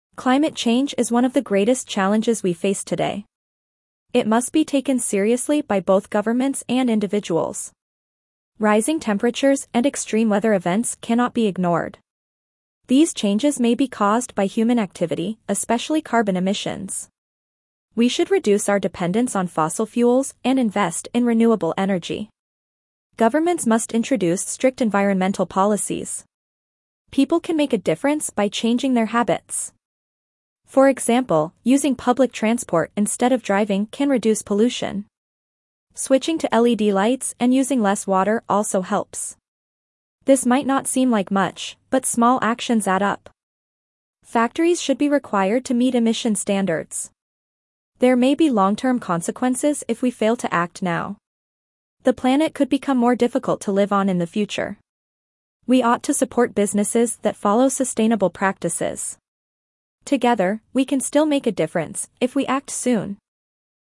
Dictation B2 - Climate Change Solutions
Your teacher will read the passage aloud.